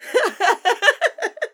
Boominati Laugh FX.wav